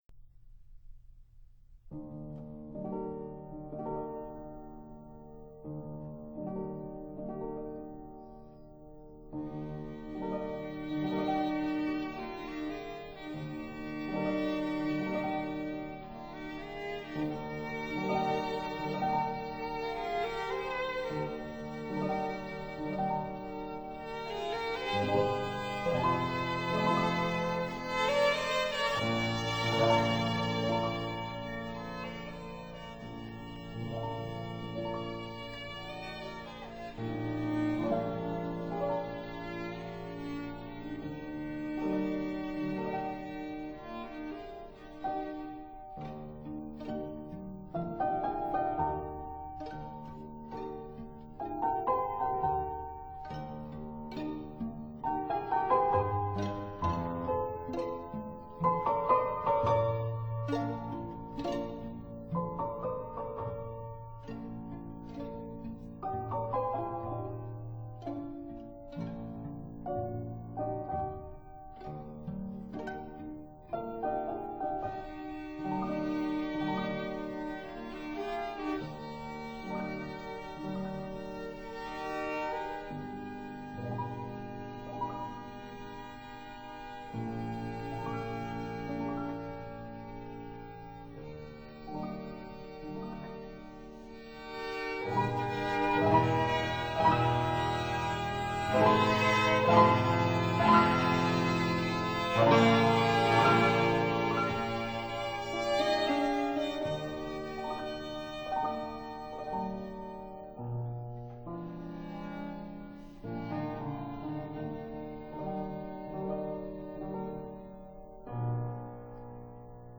Fortepiano
Violin
Cello
(Period Instruments)